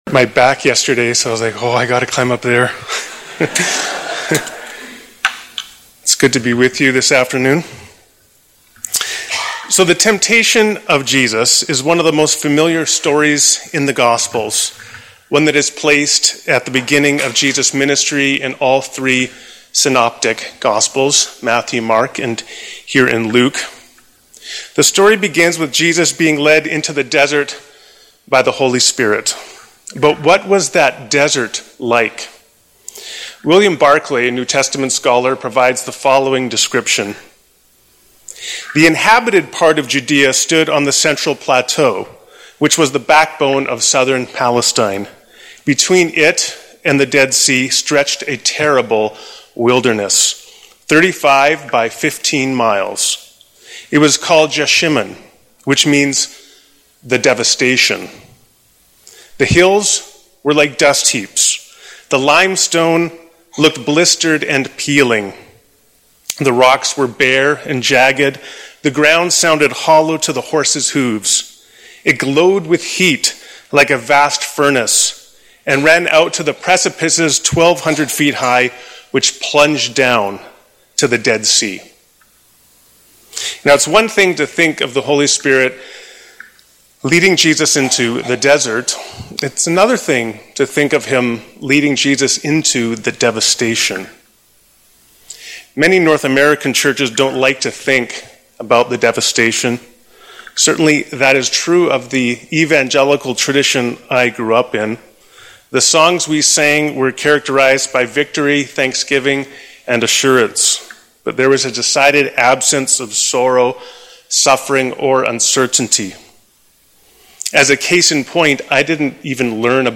Sermon Podcast for First Baptist Church of Edmonton, Alberta, Canada